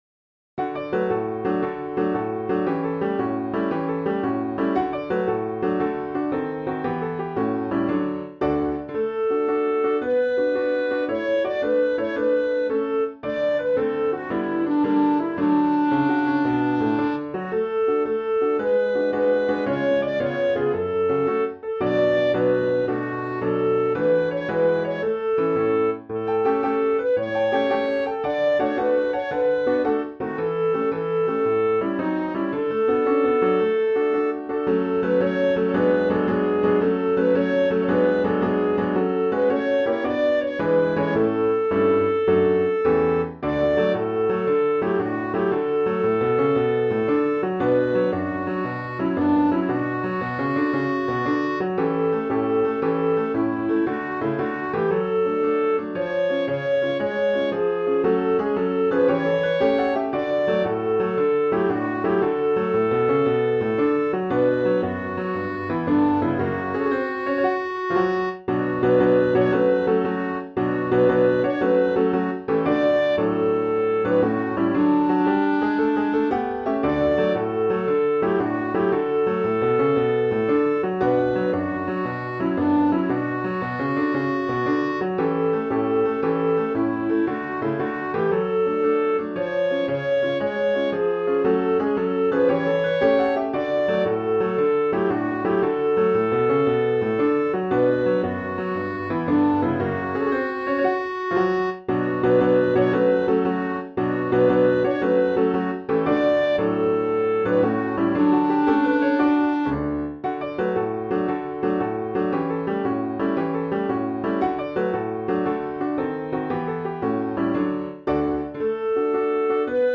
Music Hall Songs: